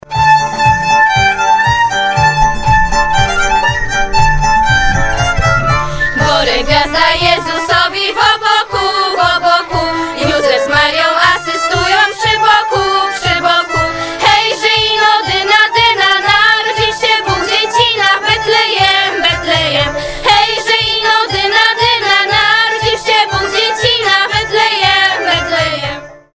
Tym razem do studia przybyli przedstawiciele chóru, którzy złożyli świąteczne życzenia oraz zaprezentowali kolędę 'Gore gwiazda Jezusowi”.